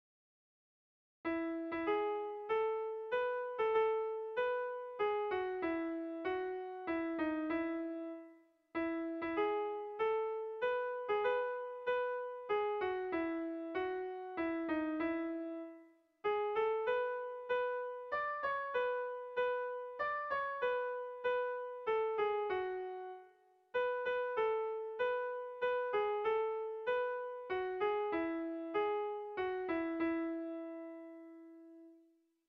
Bertso melodies - View details   To know more about this section
Erlijiozkoa
AABD